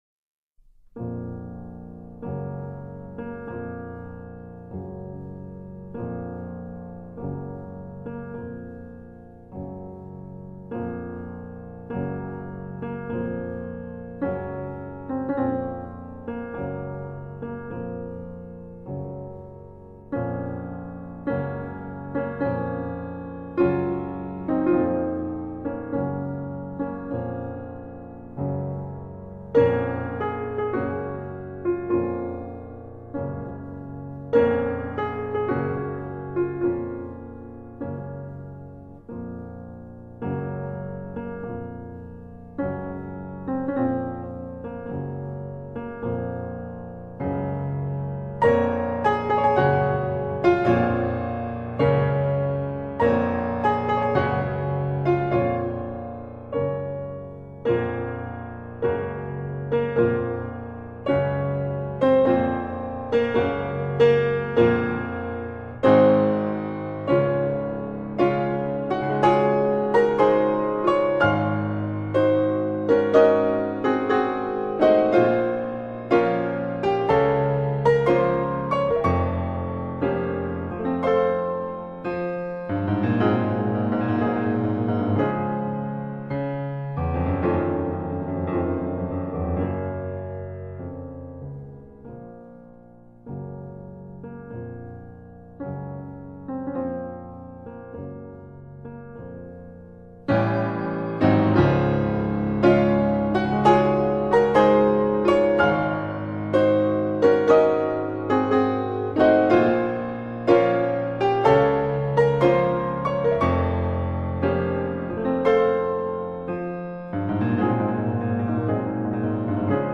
Chopin-Sonata-n-2-op-35-Marcia-Funebre-Pianoforte.mp3